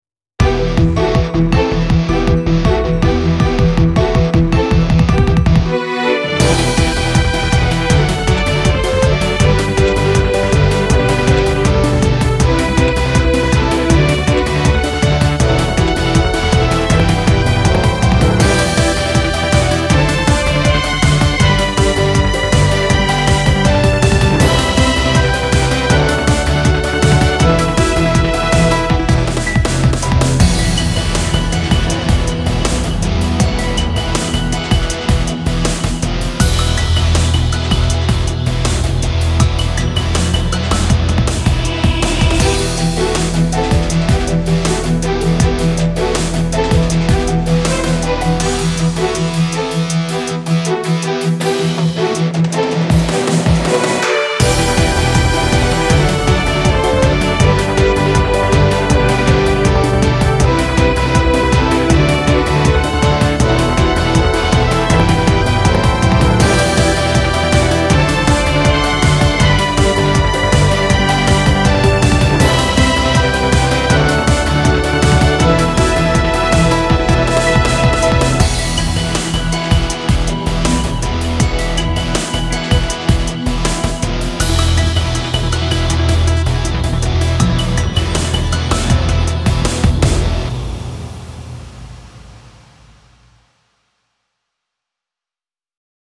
最終決戦をイメージしたインスト曲です。激しめのバトルシーンを想定しています。ループしません。